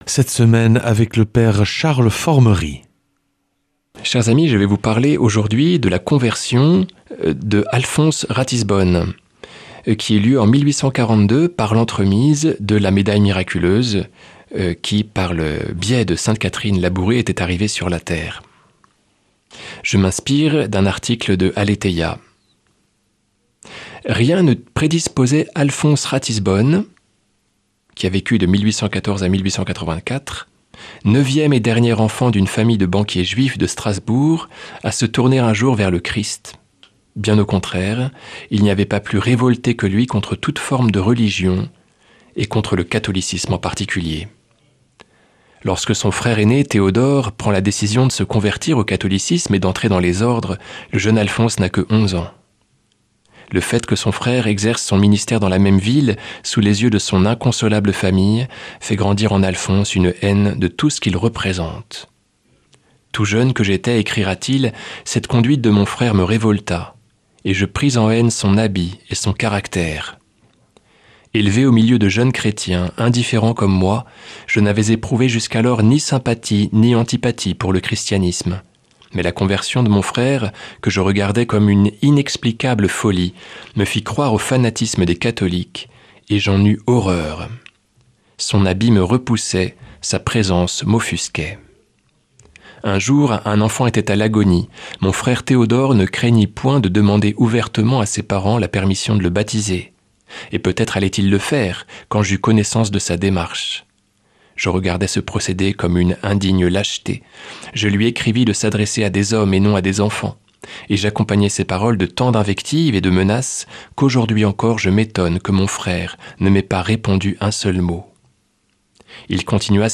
mardi 25 novembre 2025 Enseignement Marial Durée 10 min